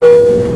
add sounds from a320neo
chime.wav